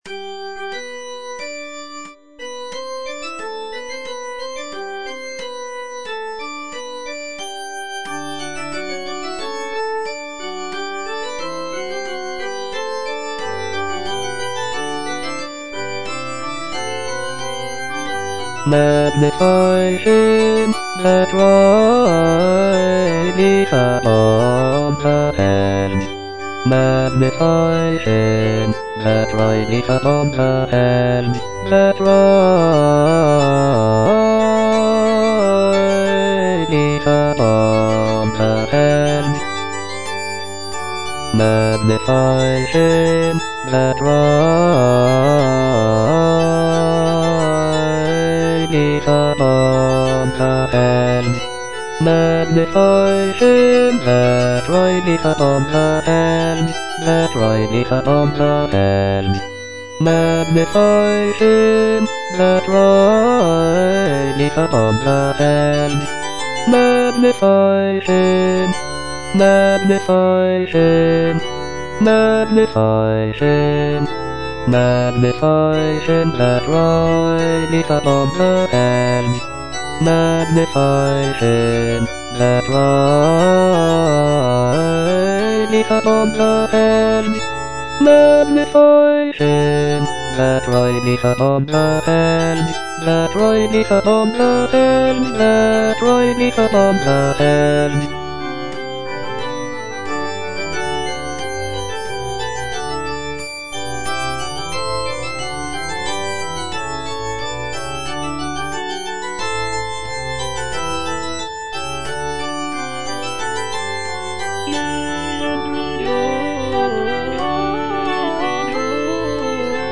T. LINLEY - LET GOD ARISE Magnify him - Bass (Voice with metronome) Ads stop:
"Let God arise" is a sacred choral work composed by Thomas Linley the younger, an English composer and conductor. Written in the Baroque style, the piece features a majestic and uplifting melody that conveys a sense of reverence and awe.